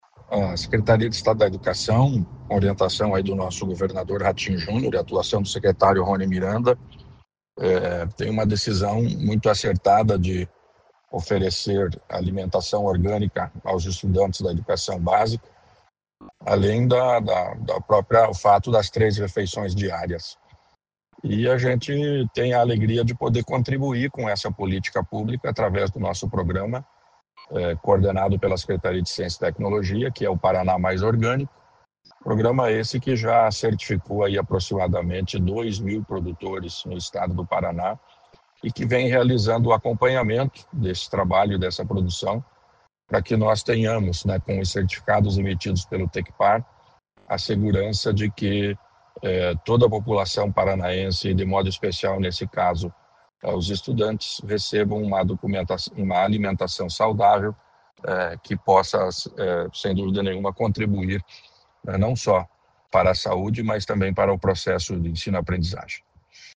Sonora do secretário Estadual da Ciência, Tecnologia e Ensino Superior, Aldo Bona, sobre o incentivo a produção orgânica para a merenda das escolas estaduais